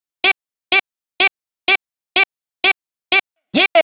cch_vocal_loop_it_125.wav